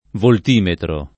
voltimetro [ volt & metro ]